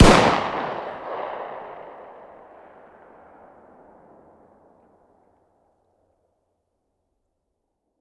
fire-dist-40sw-pistol-ext-02.ogg